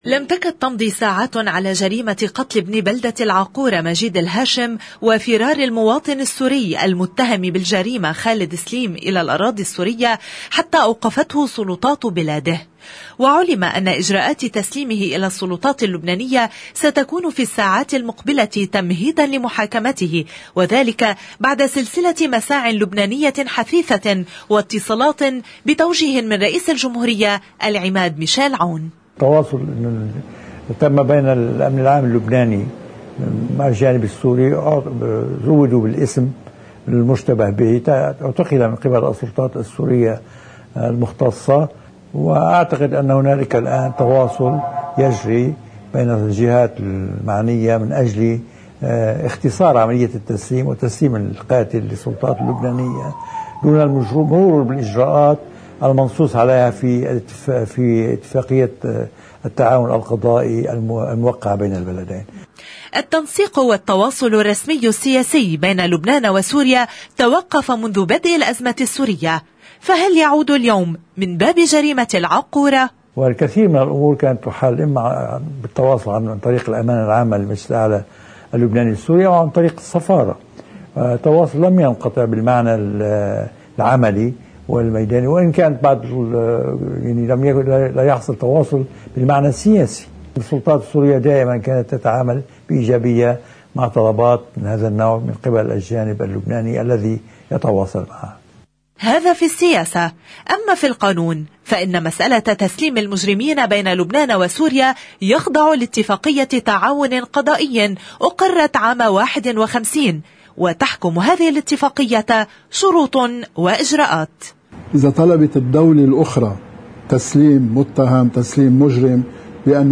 بالصوت تقرير مفصّل عن موضوع جريمة العاقورة (Video+Audio)